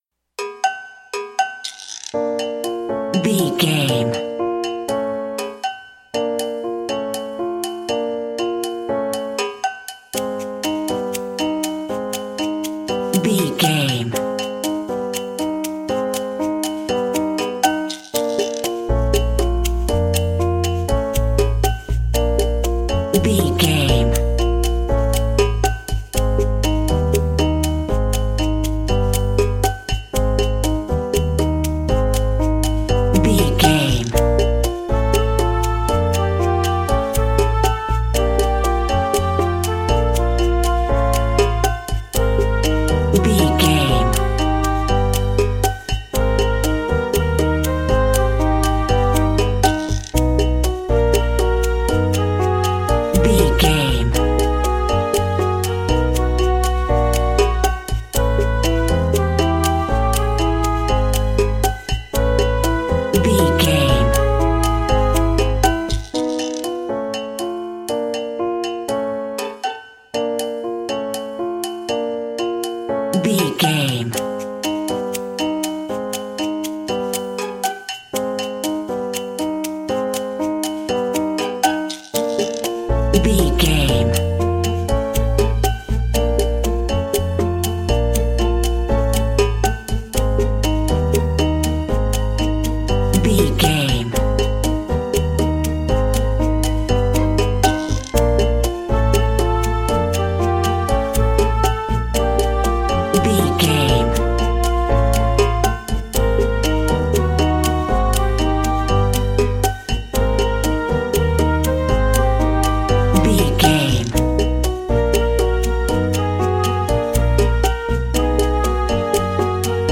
Aeolian/Minor
percussion
flutes
piano
orchestra
double bass
silly
circus
goofy
comical
cheerful
perky
Light hearted
quirky